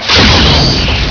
000phaser1.wav